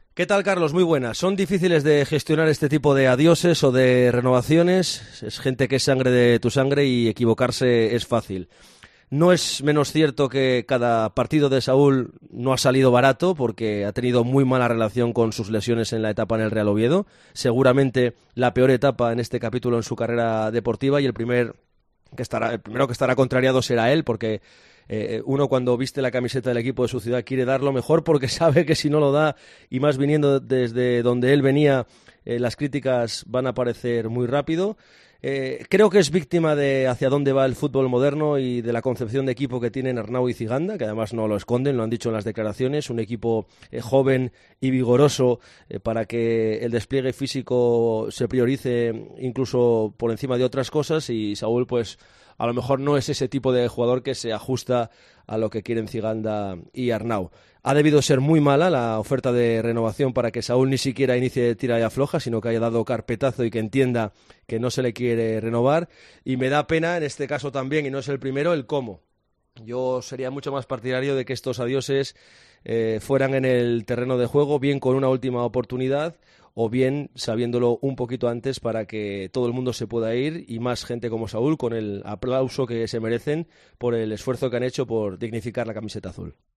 EL ANÁLISIS